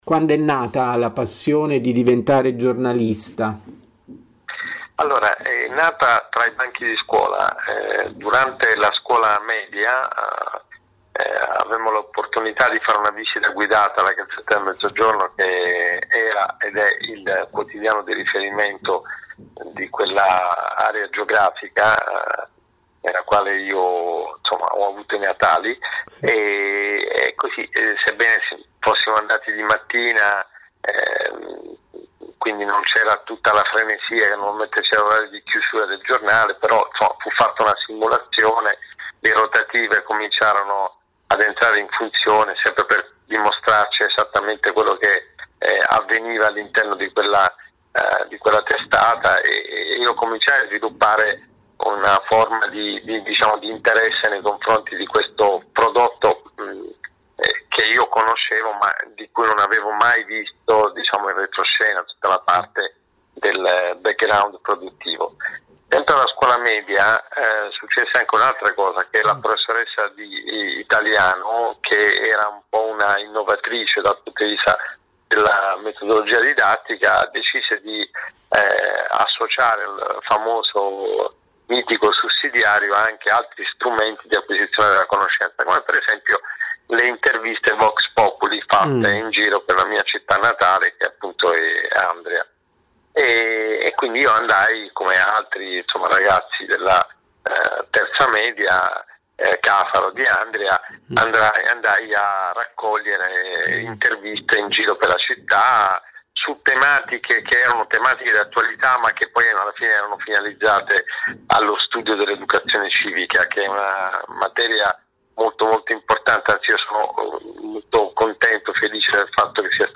L’intervista a Città Nuova